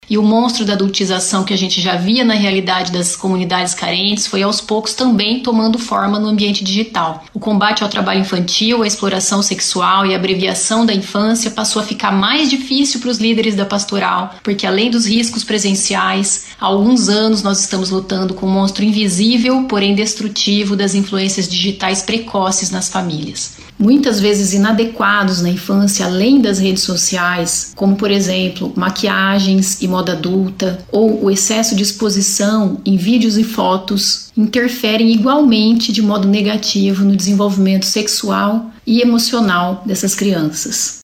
SONORA-PASTORAL-DA-CRIANCA.mp3